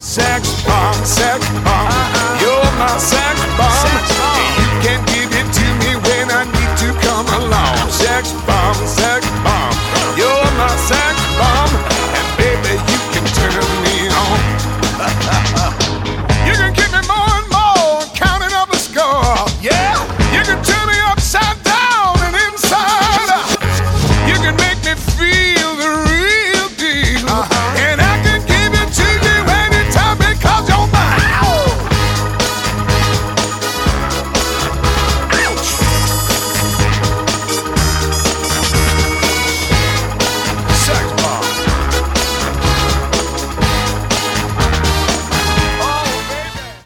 • Качество: 192, Stereo
поп
веселые
Британский эстрадный певец